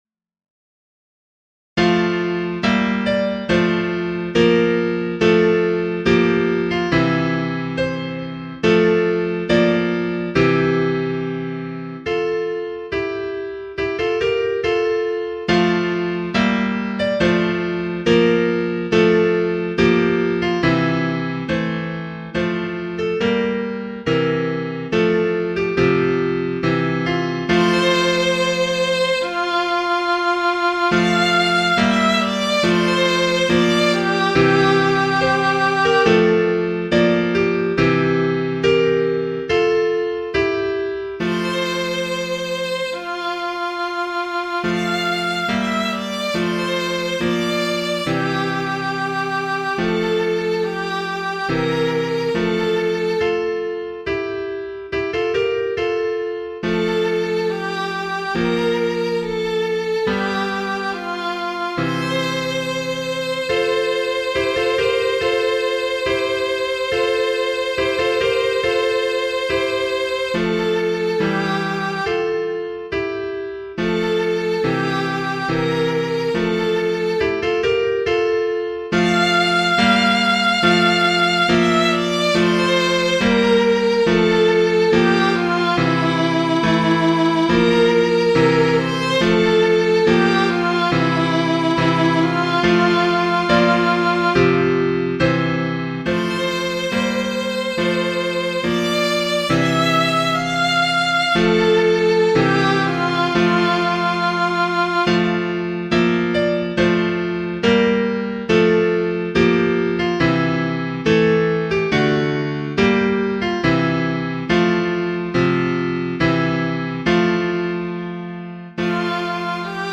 Haendel, G. F. Genere: Barocco Author: Thomas Morell Angels, ever bright and fair, Take, oh take me to your care; Speed to your own courts my flight, Clad in robes of virgin white.